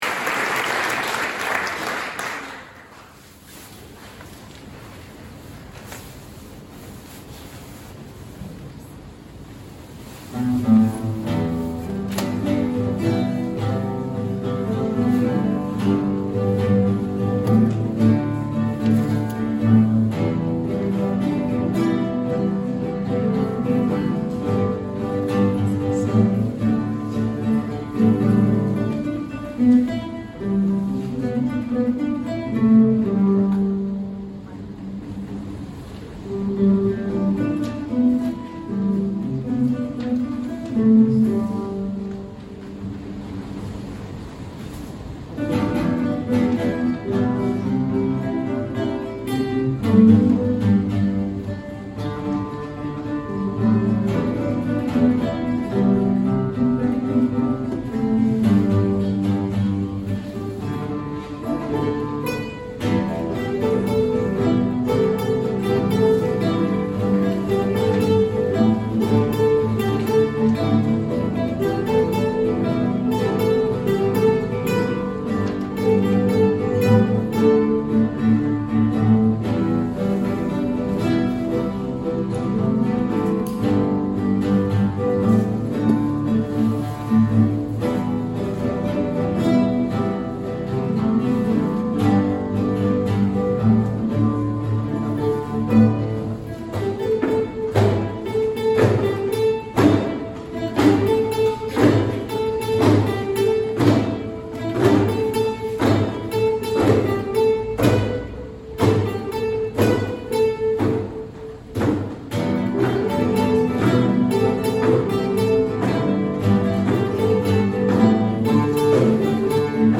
Cool Kids | Guitar Platform Ensemble